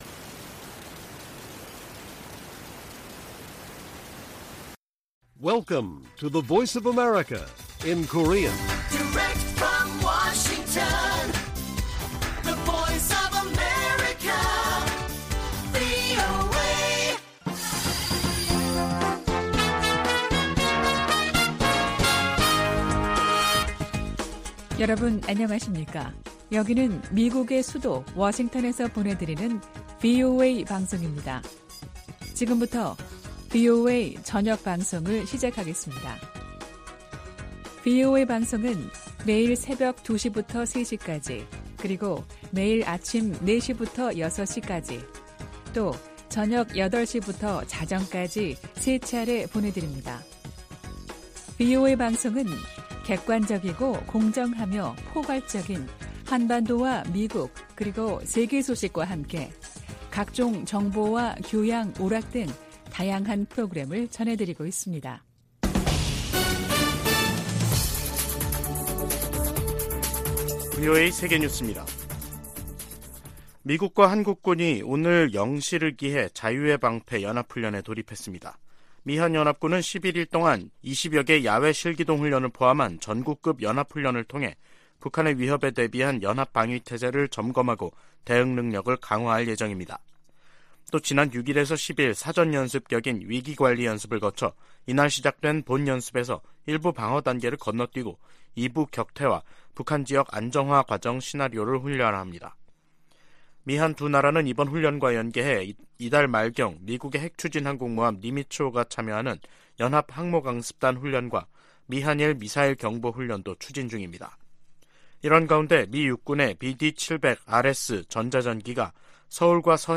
VOA 한국어 간판 뉴스 프로그램 '뉴스 투데이', 2023년 3월 13일 1부 방송입니다. 북한이 12일 전략순항미사일 수중발사훈련을 실시했다고 다음날 대외 관영 매체들이 보도했습니다. 미국과 한국은 ‘자유의 방패’ 연합연습을 시작했습니다. 미 국무부가 북한의 최근 단거리 탄도미사일 발사를 규탄하며 대화 복귀를 촉구했습니다.